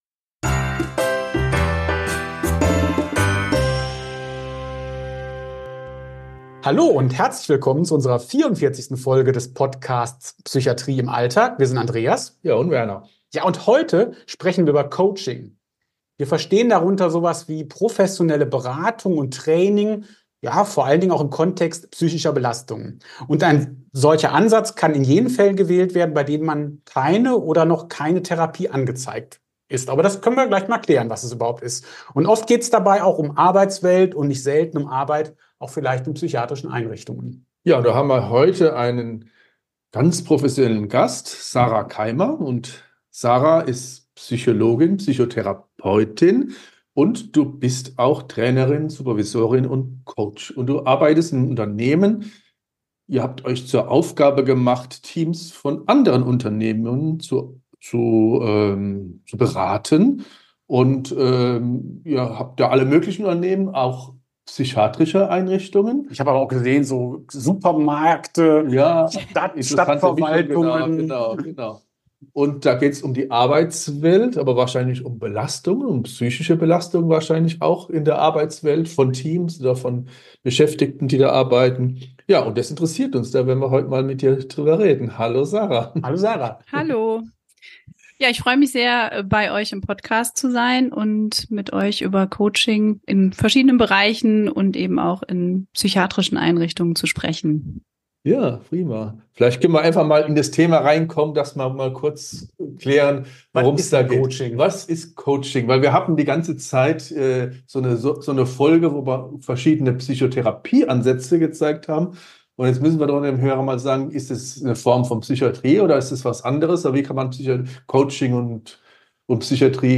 Im Gespräch mit ihr werden Grundlagen, Einsatzmöglichkeiten und Praxisbeispiele besprochen.